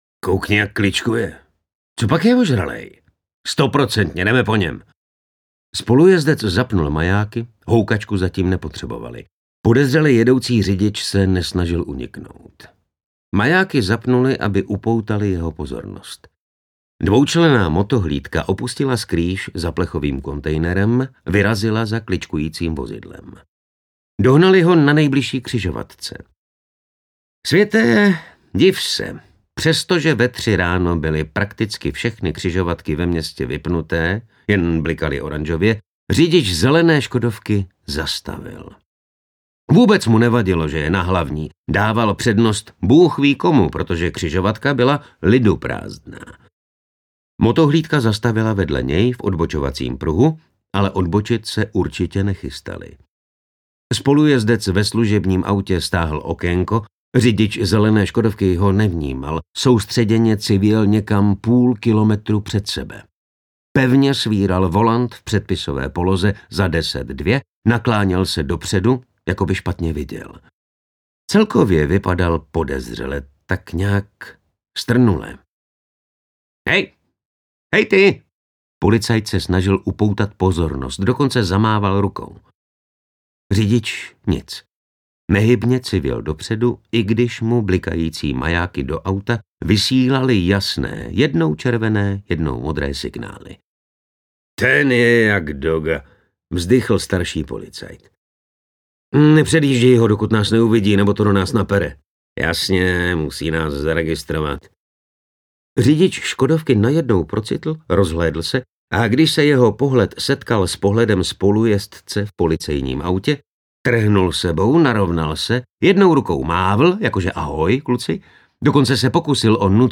Břemeno minulosti audiokniha
Ukázka z knihy